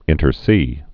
(ĭntər sē, sā)